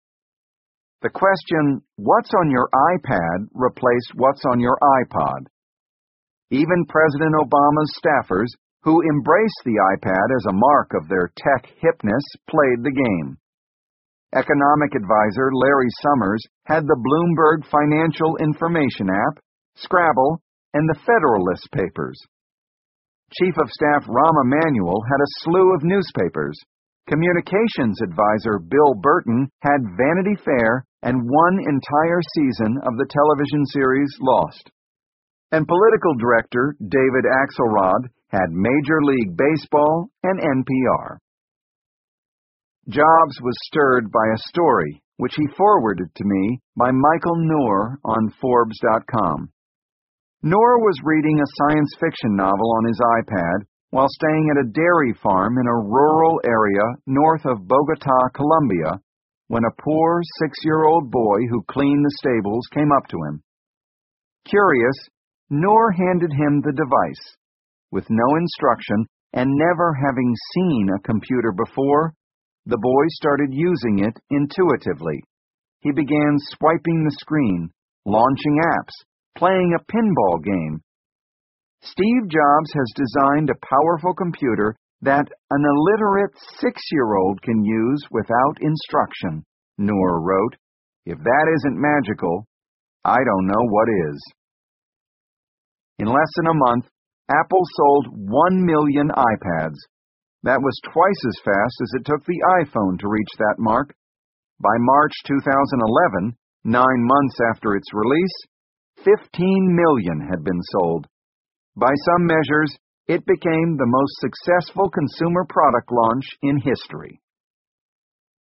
在线英语听力室乔布斯传 第690期:2010年1月，iPad发布(7)的听力文件下载,《乔布斯传》双语有声读物栏目，通过英语音频MP3和中英双语字幕，来帮助英语学习者提高英语听说能力。
本栏目纯正的英语发音，以及完整的传记内容，详细描述了乔布斯的一生，是学习英语的必备材料。